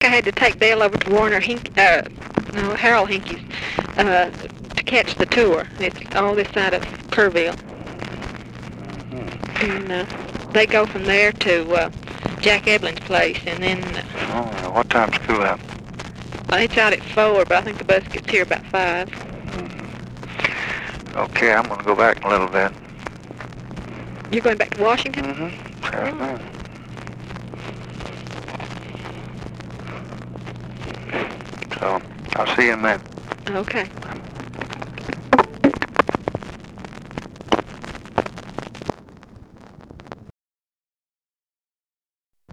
Conversation
Secret White House Tapes